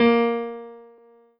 piano-ff-38.wav